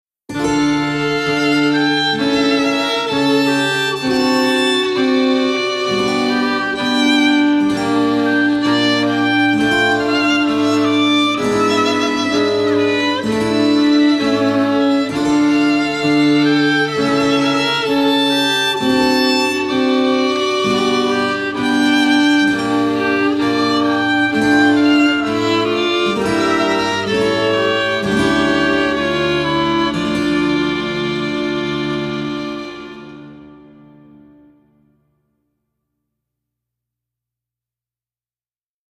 clavecin - courtisan - epoque